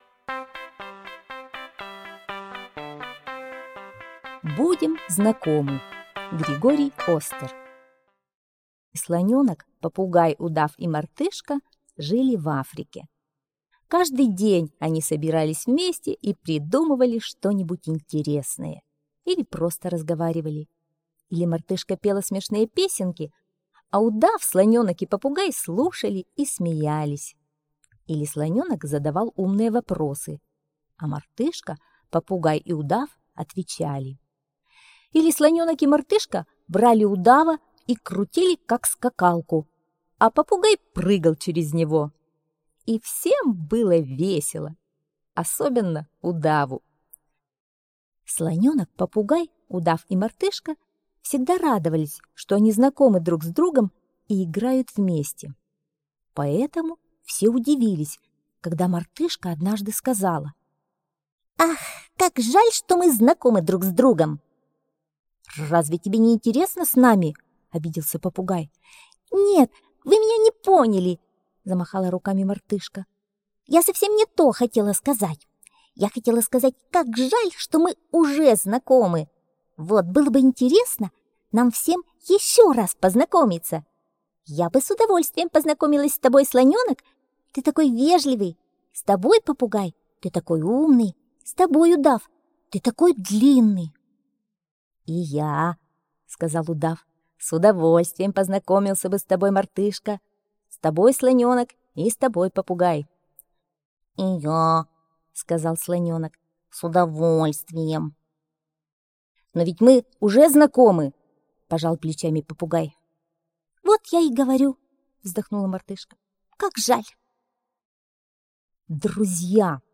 Будем знакомы - аудиосказка Остера Г.Б. Забавная история про то, как мартышка предложила своим друзьям познакомиться друг с другом еще раз!